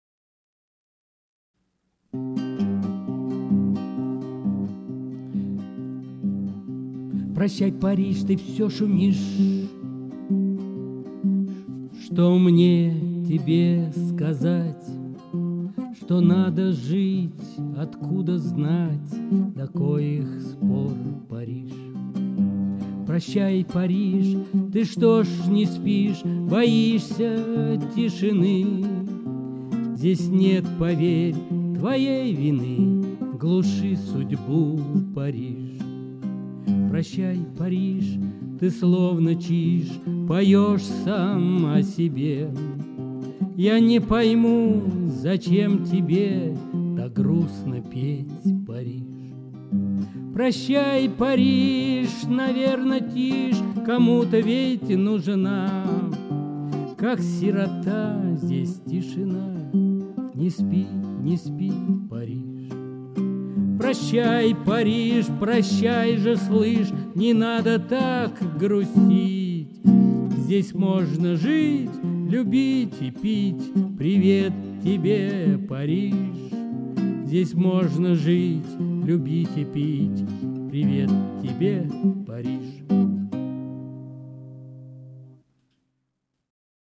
Music, vocal, guitar